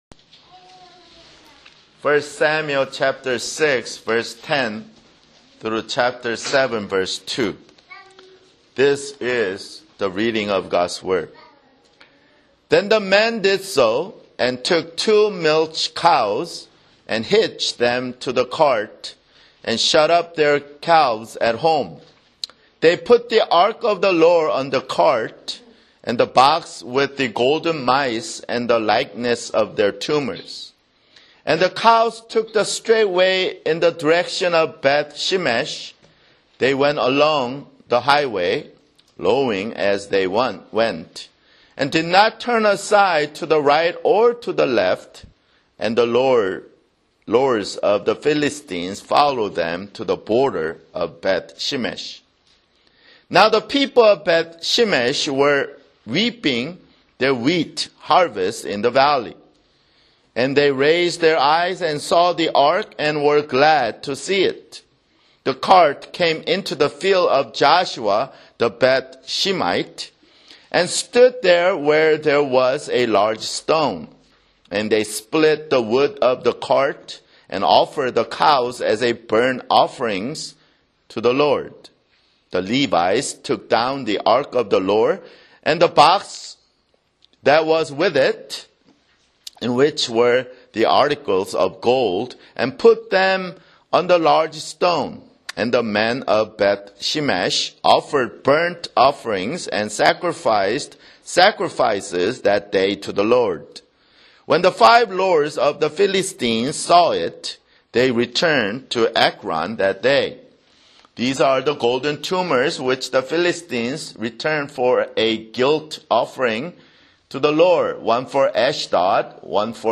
Sunday, July 17, 2011 [Sermon] 1 Samuel (21) 1 Samuel 6:10-7:2